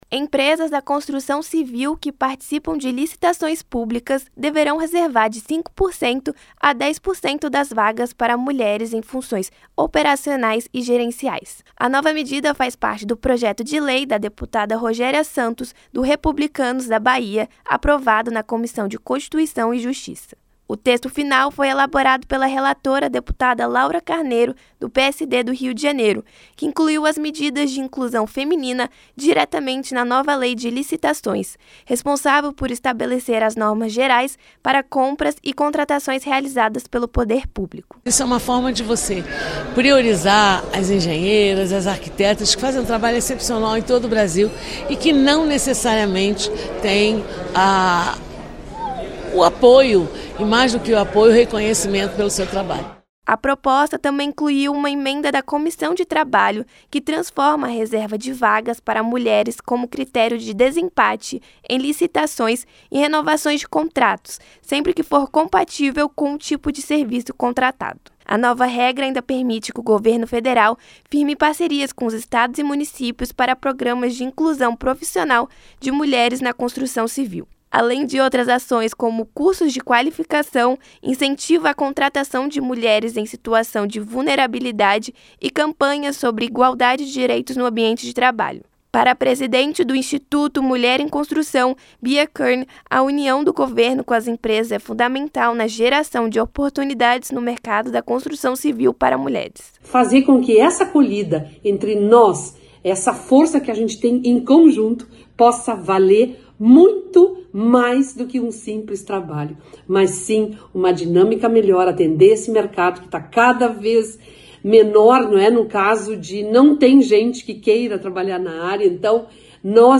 COMISSÃO APROVA NOVA REGRA QUE INCENTIVA CONTRATAÇÃO DE MULHERES NA CONSTRUÇÃO CIVIL. A REPÓRTER